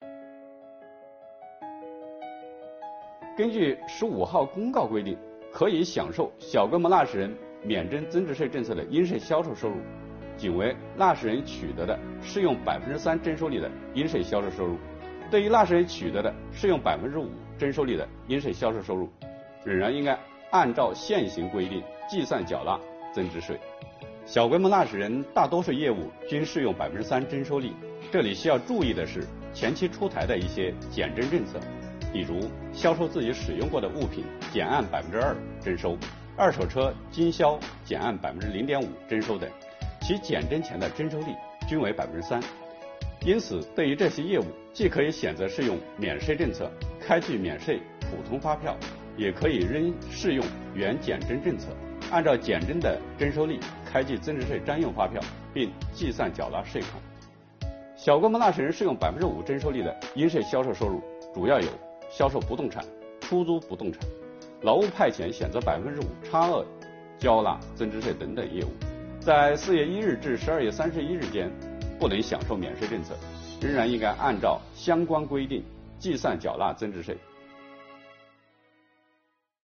本期课程由国家税务总局货物和劳务税司副司长刘运毛担任主讲人，对小规模纳税人免征增值税政策进行详细讲解，方便广大纳税人进一步了解掌握相关政策和管理服务措施。